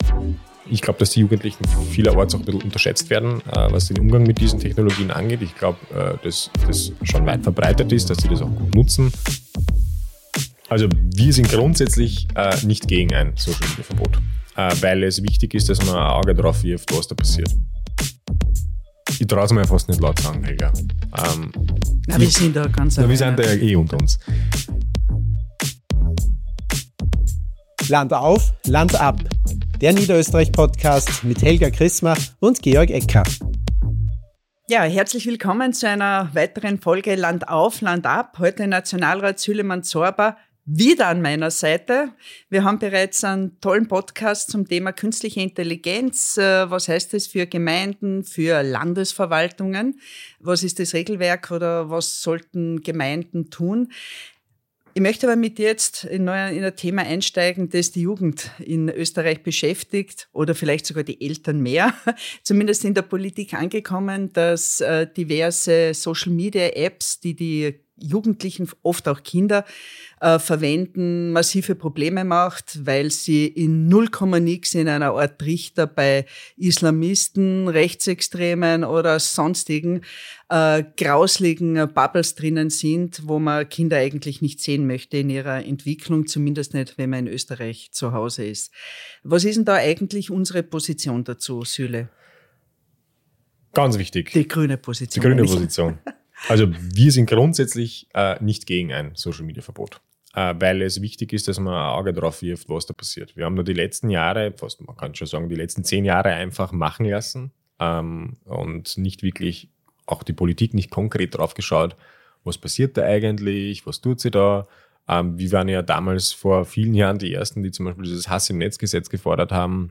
Nationalratsabgeordneter Süleyman Zorba spricht mit Helga Krismer über das geplante Social-Media-Verbot für Jugendliche, algorithmische Radikalisierung, Jugendschutz und Meinungsfreiheit. Ausgehend von aktuellen Plänen in Europa und Ländern wie Spanien beleuchten die beiden, wie Abhängigkeit von Tech-Konzernen entsteht, warum digitale Oligarchen zur Gefahr für Demokratie und Rechtsstaat werden können und welche Rolle europäische Regeln, Steuern und Bildungsarbeit dabei spielen.